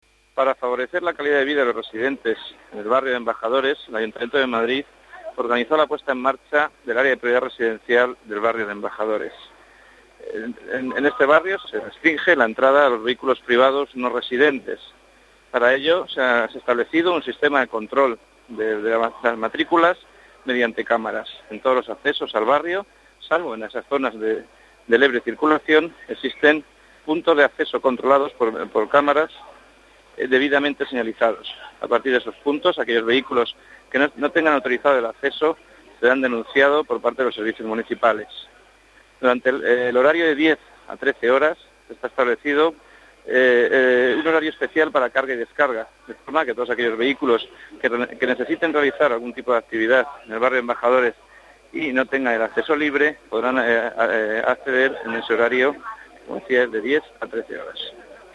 Nueva ventana:Director movilidad, Javier Conde: cámaras tráfico restringido en Embajadores